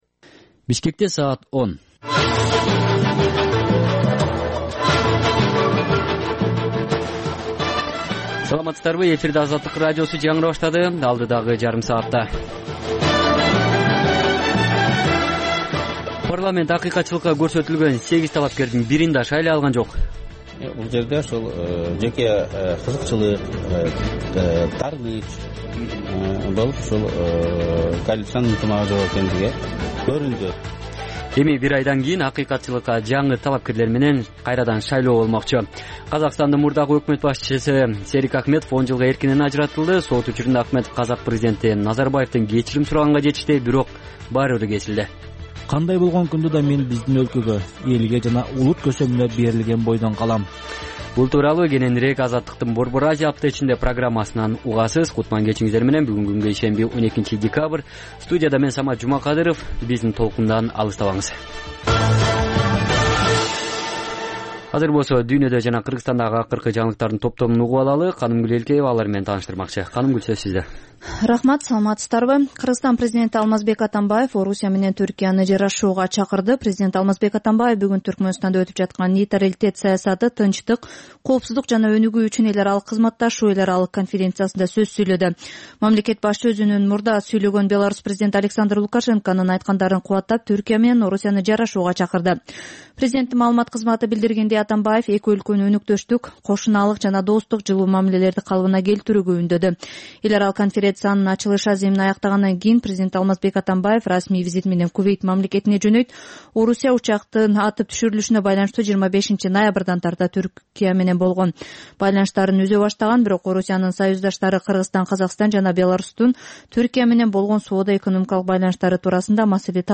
Кечки 10догу кабарлар